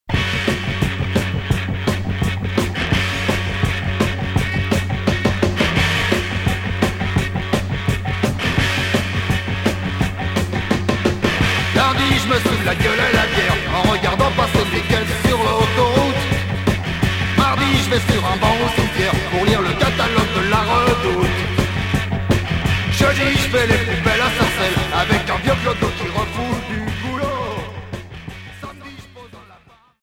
Punk rock Premier 45t retour à l'accueil